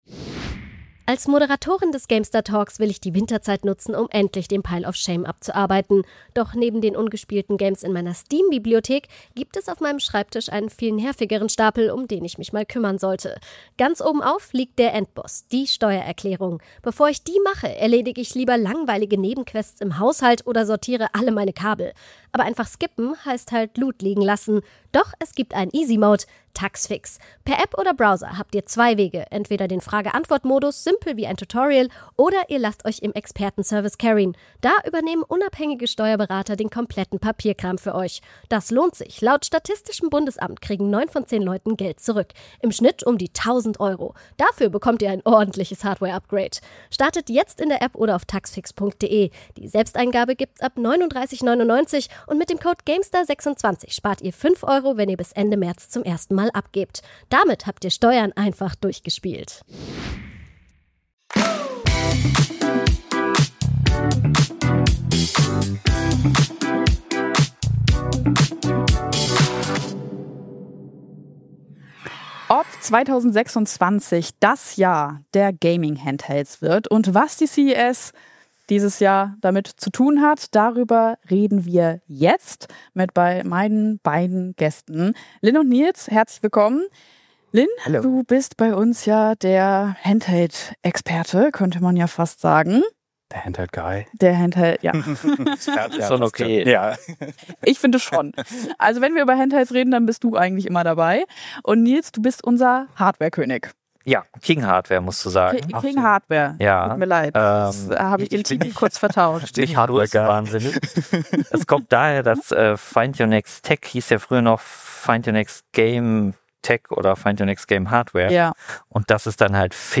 Im GameStar Podcast sprechen die Redaktionen von GameStar, GamePro und MeinMMO über die Themen, die sie bewegen